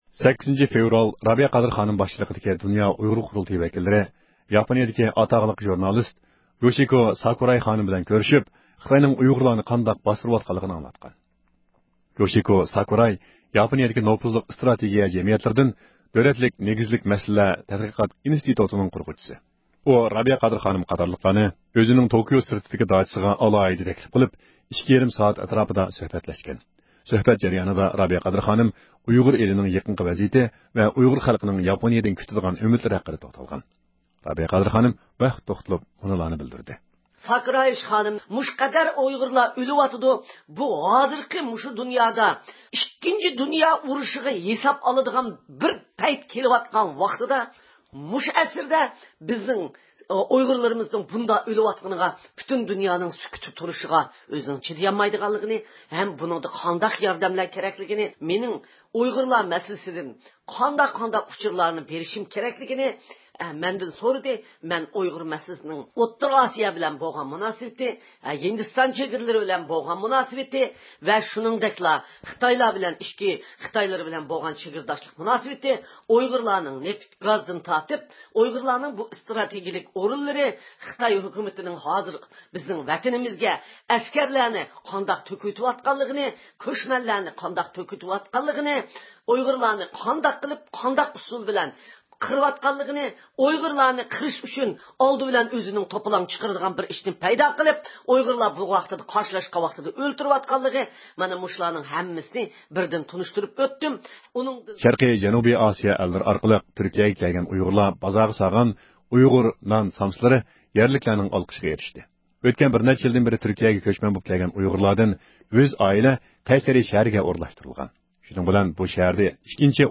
ھەپتىلىك خەۋەرلەر (7-فېۋرالدىن 13-فېۋرالغىچە) – ئۇيغۇر مىللى ھەركىتى